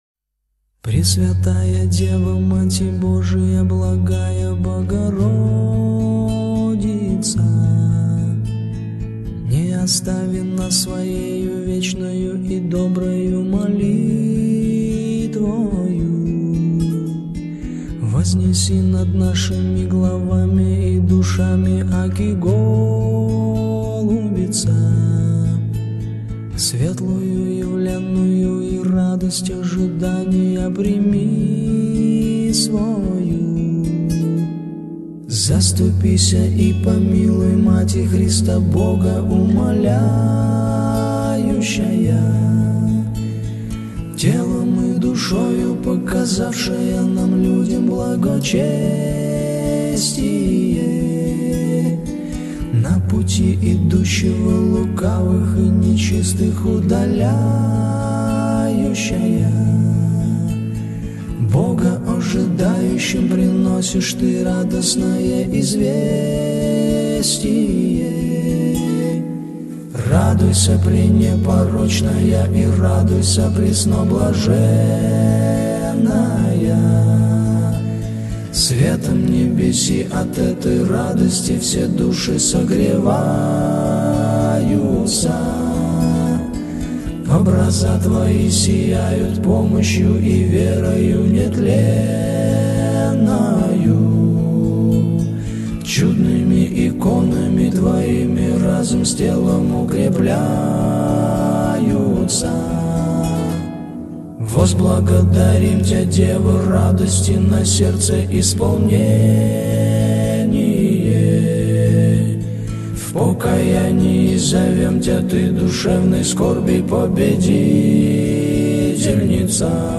(Песня-молитва ко Пресвятой Богородице)…
МОЛИТВА-К-БОГОРОДИЦЕ.mp3